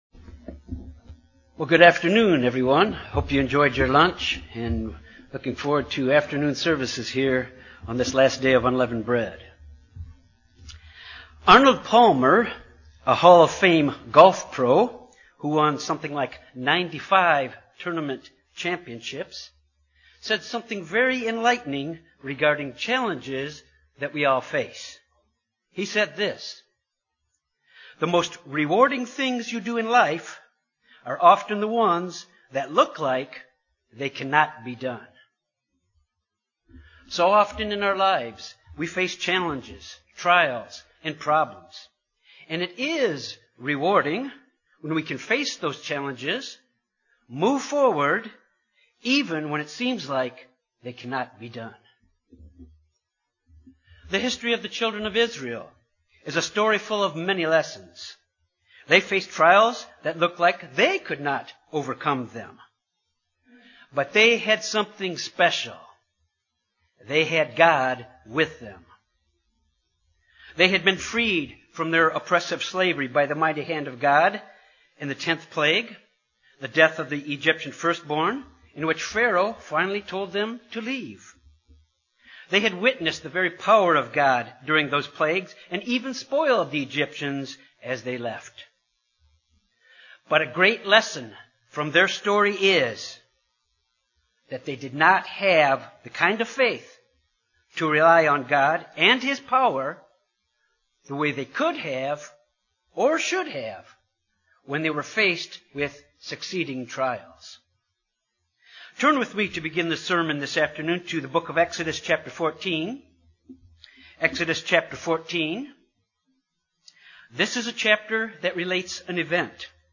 Sermons
Given in Jonesboro, AR Little Rock, AR Memphis, TN